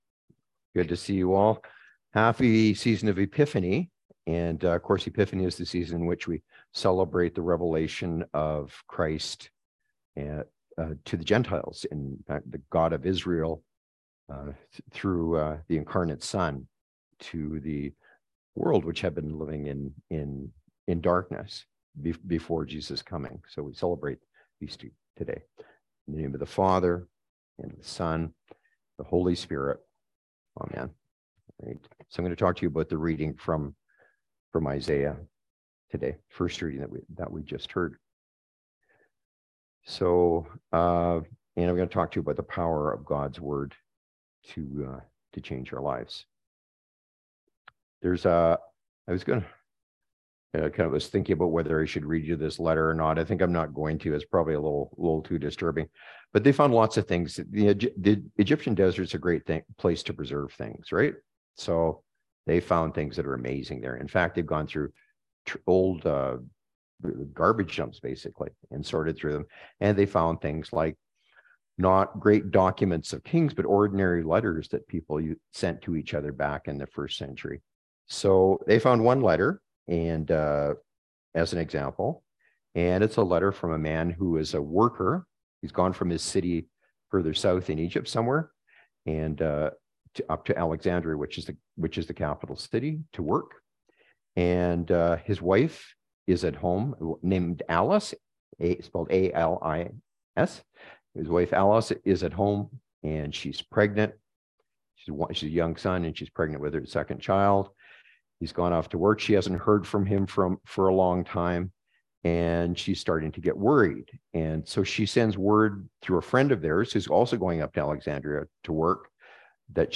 Sermons | St. George's Anglican Church